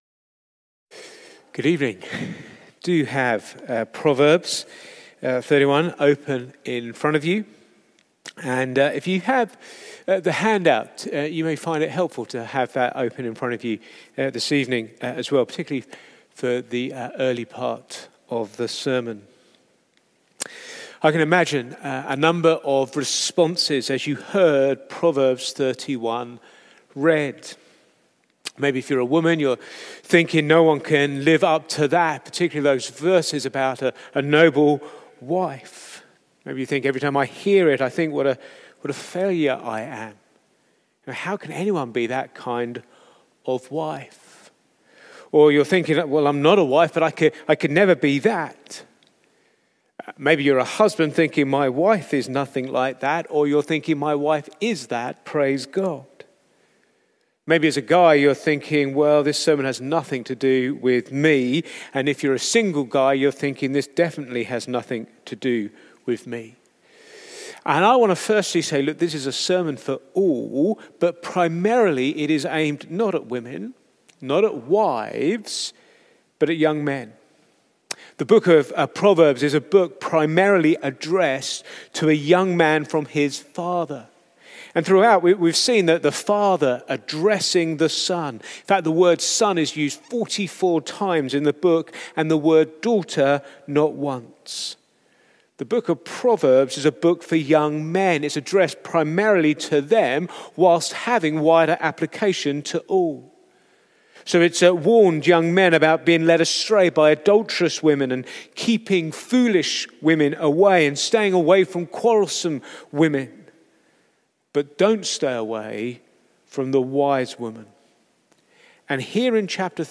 Back to Sermons The Excellent Wife